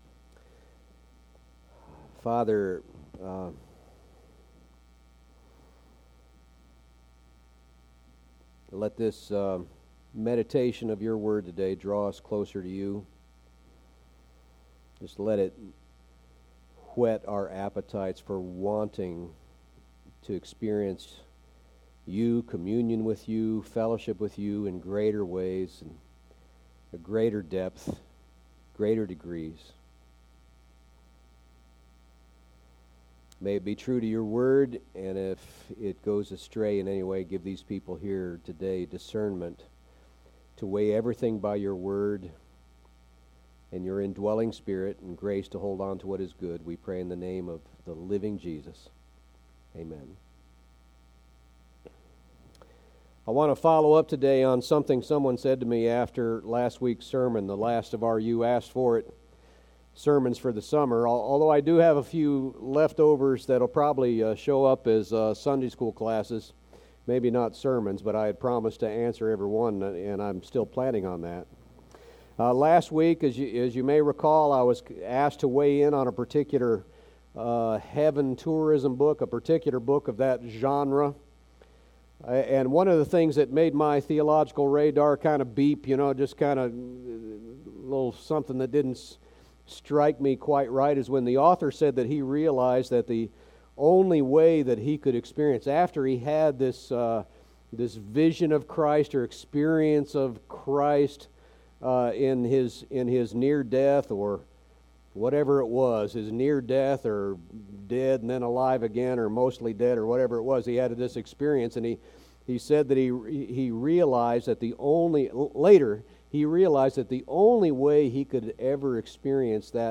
Sermons and Lessons - Faith Bible Fellowship